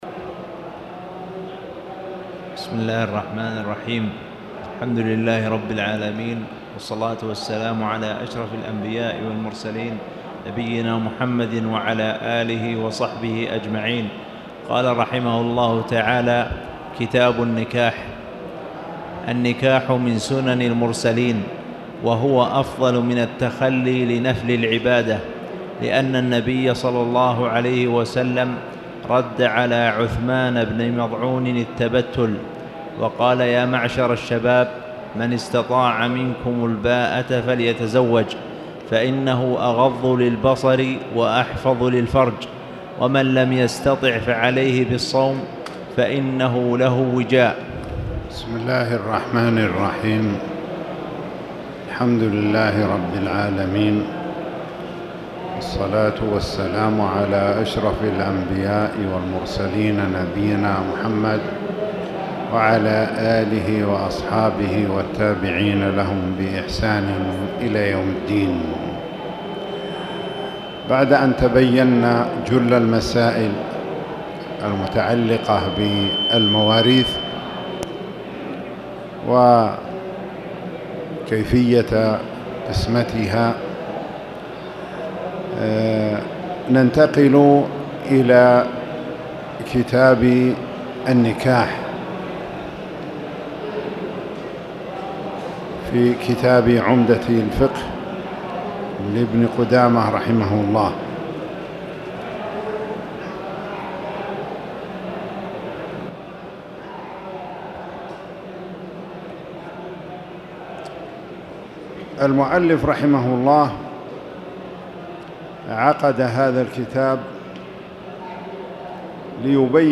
تاريخ النشر ٢٦ صفر ١٤٣٨ هـ المكان: المسجد الحرام الشيخ